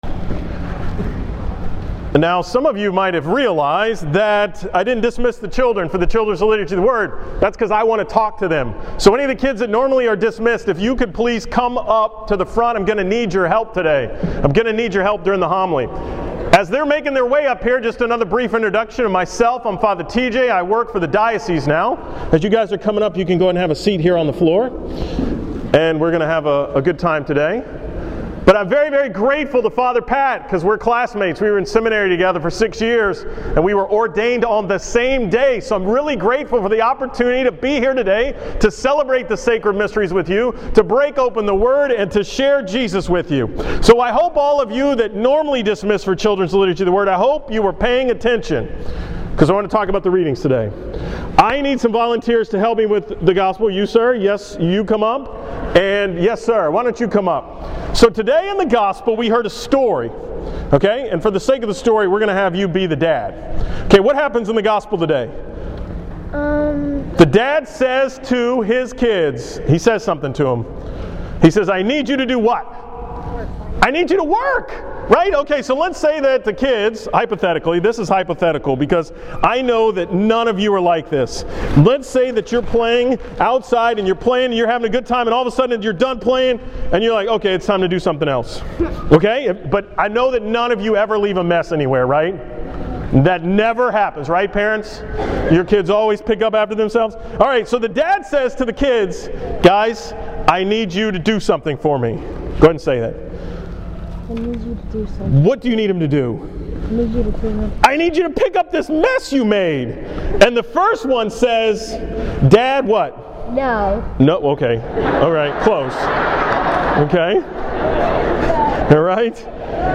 From the 26th Sunday in Ordinary time at St. Simon and Jude in The Woodlands